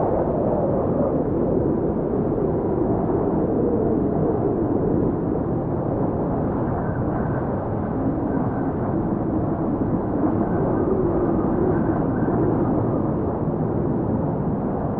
Free Nature sound effect: Wind Howling.
Wind Howling
Wind Howling is a free nature sound effect available for download in MP3 format.
333_wind_howling.mp3